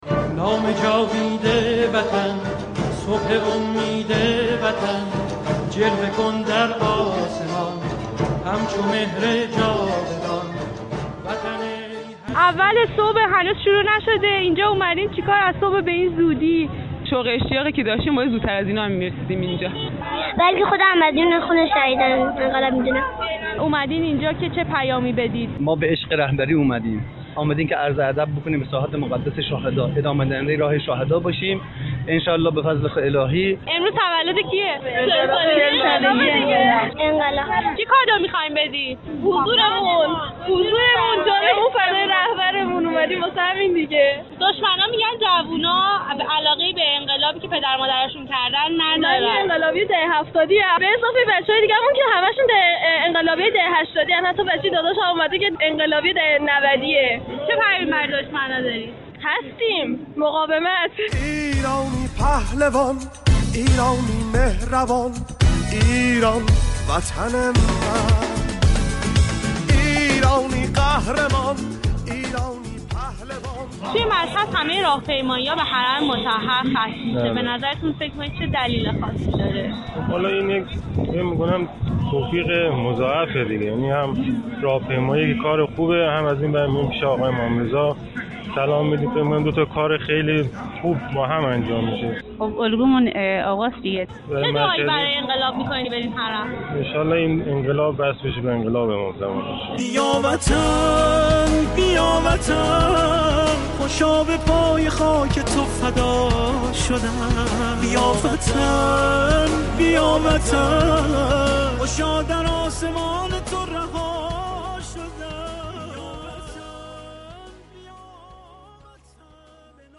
در مشهد مقدس راهپیمایی از 9 صبح آغاز شده اما مردم از ساعتی پیش در میدان 15 خرداد حضور یافته اند .
مردم از میدان 15 خرداد تا حرم مطهرضوی فریاد سر میدهند